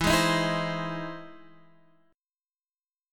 E+M7 chord {x 7 6 8 5 8} chord